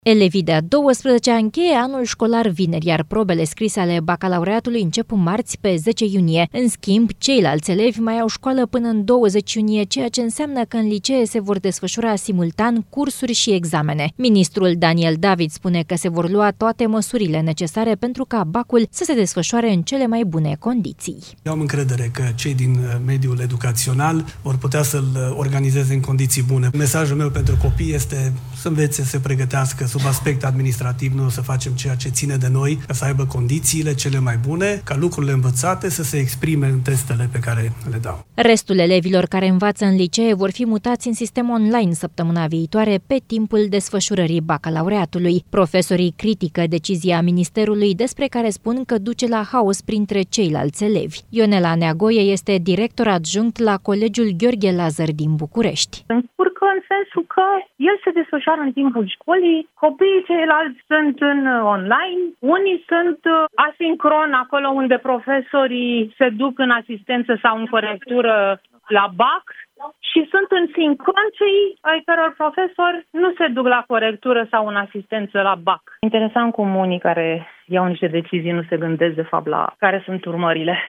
„Eu am încredere că cei din mediul educațional vor putea să-l organizeze în condiții bune. Mesajul meu pentru copii este să învețe, să se pregătească. Sub aspect administrativ o să facem tot ce ține de noi să aiba cele mai bune condiții”, spune Daniel David.